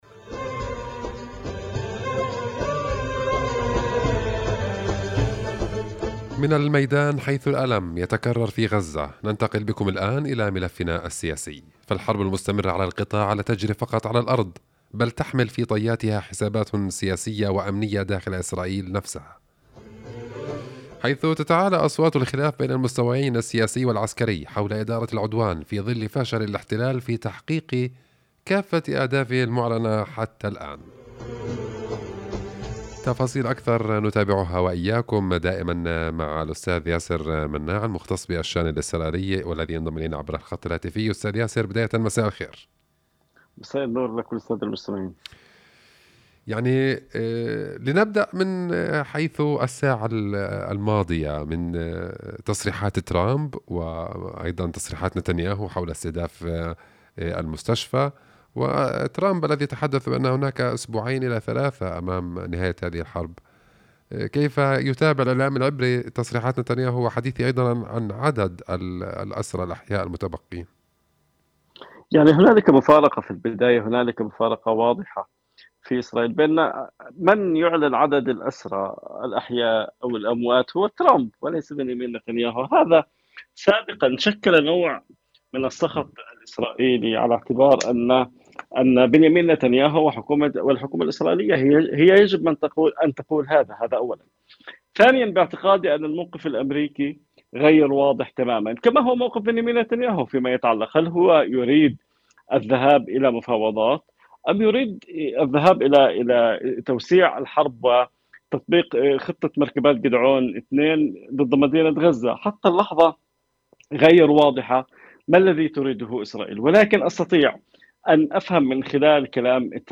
خاص – قناة القدس تناولت حلقة اليوم من جولة القدس المسائية عبر اذاعة قناة القدس